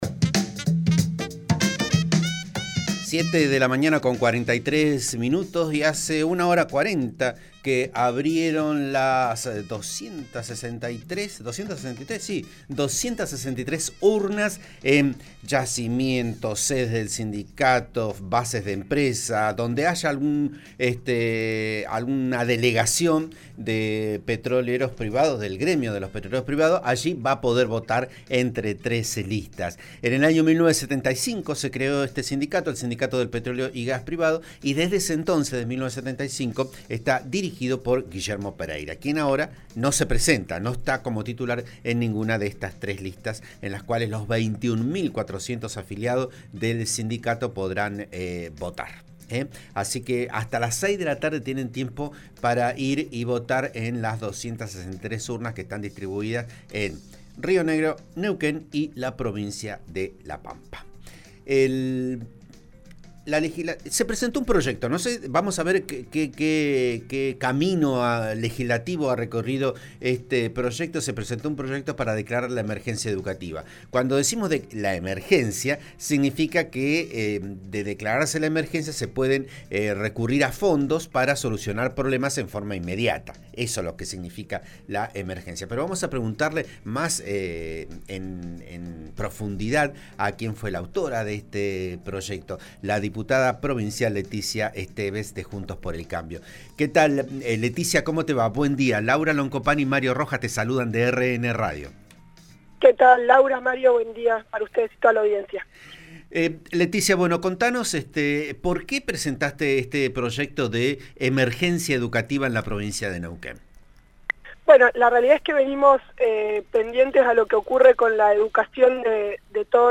Escuchá la entrevista en Vos a Diario, por RN RADIO (89.3) a la diputada Leticia Esteves: